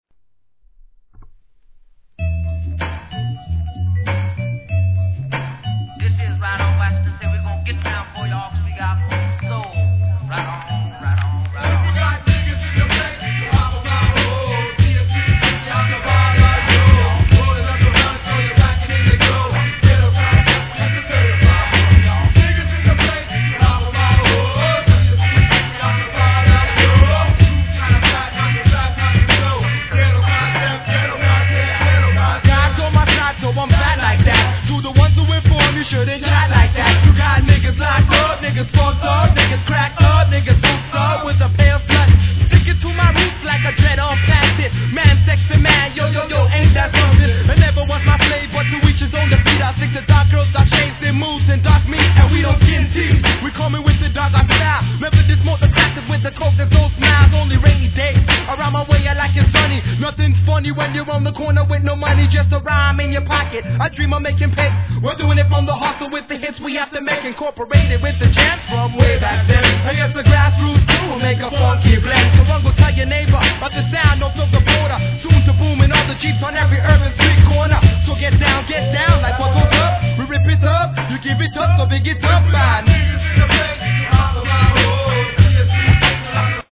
HIP HOP/R&B
ド太いベースラインとそのサンプリング術はさすが!!